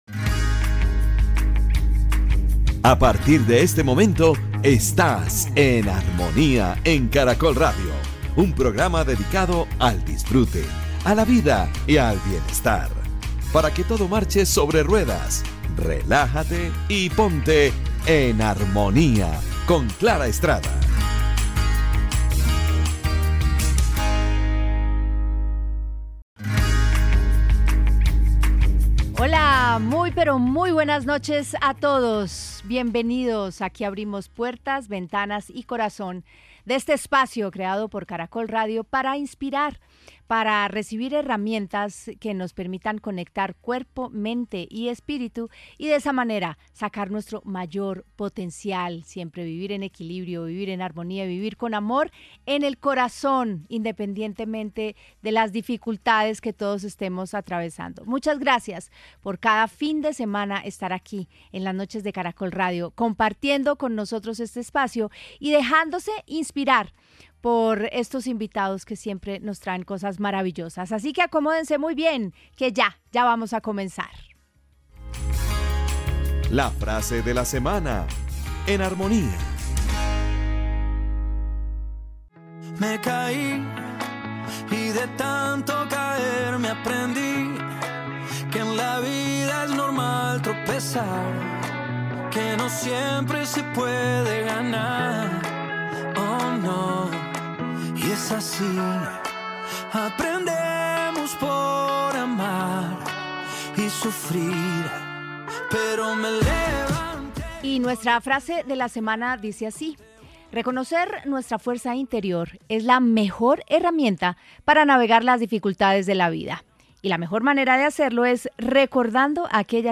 Una conversación profunda y transformadora, para alcanzar el equilibrio entre el pasado y el presente.